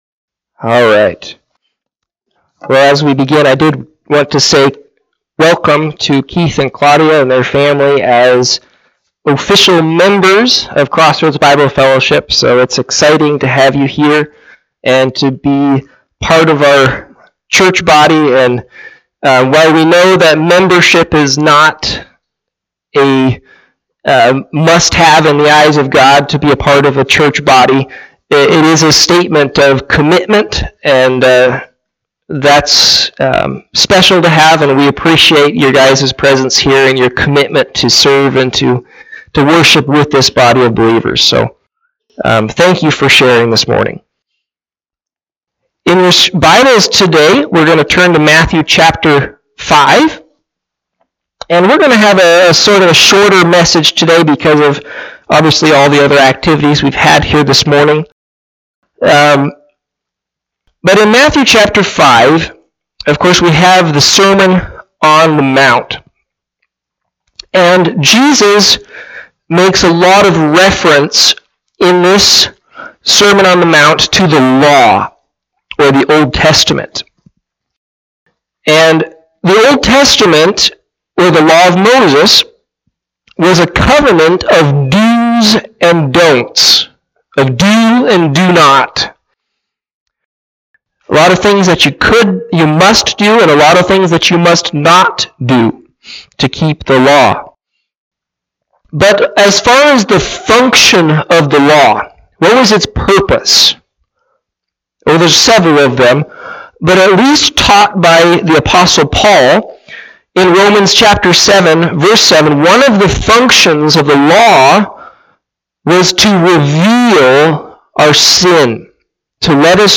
Sermons | Crossroads Bible Fellowship | Page 11